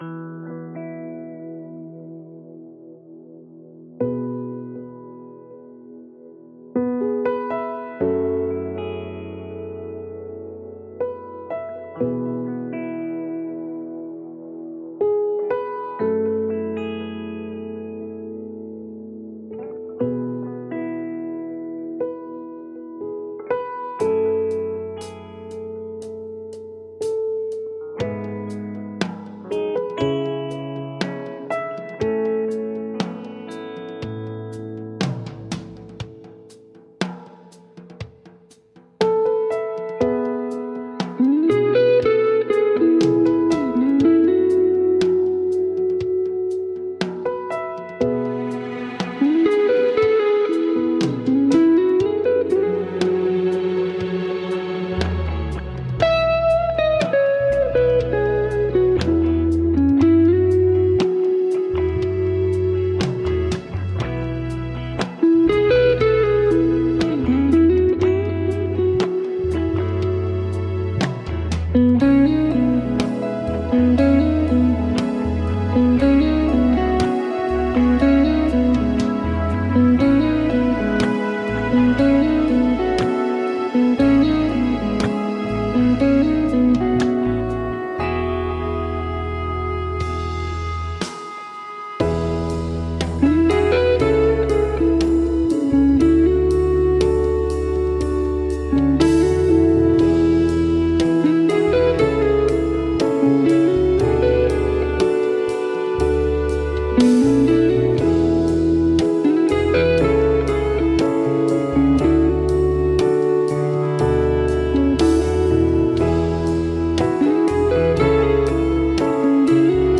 Soulful electric guitar in a smooth and gentle landscape.